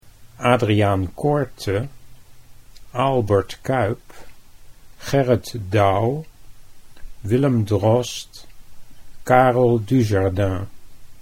How to Pronounce the Names of Some Dutch Painting Masters